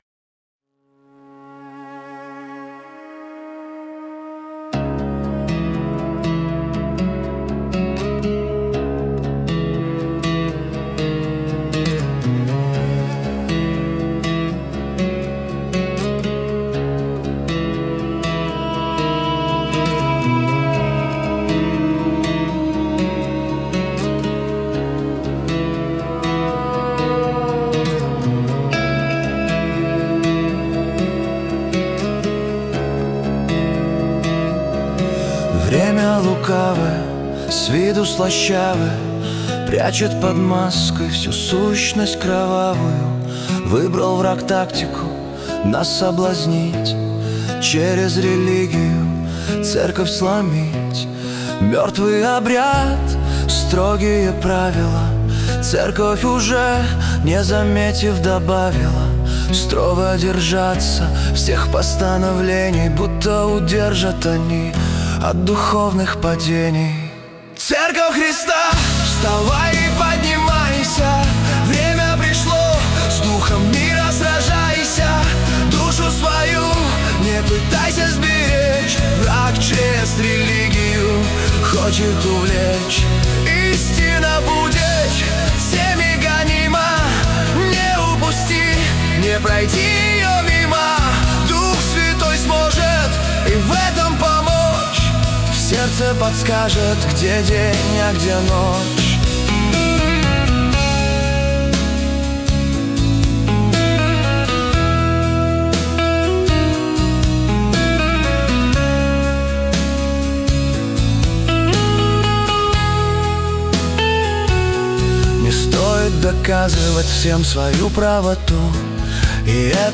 песня ai
140 просмотров 583 прослушивания 80 скачиваний BPM: 120